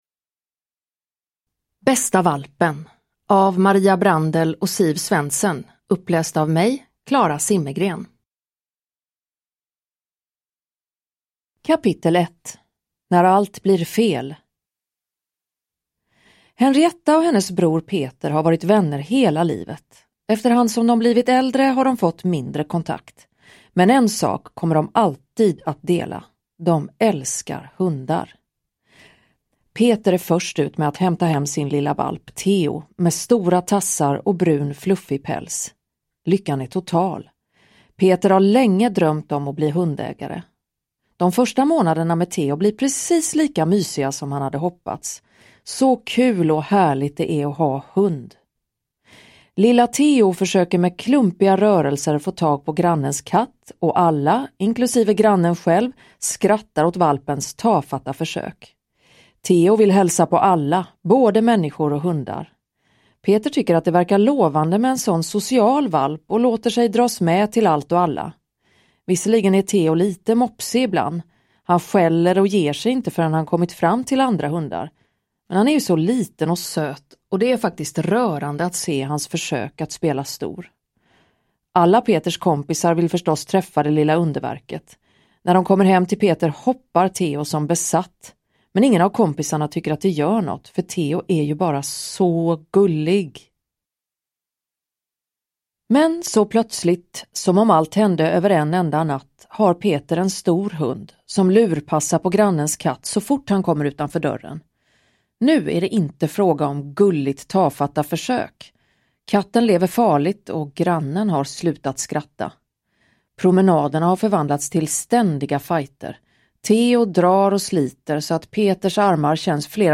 Bästa Valpen – Ljudbok
Uppläsare: Klara Zimmergren